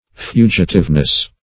Search Result for " fugitiveness" : The Collaborative International Dictionary of English v.0.48: Fugitiveness \Fu"gi*tive*ness\, n. The quality or condition of being fugitive; evanescence; volatility; fugacity; instability.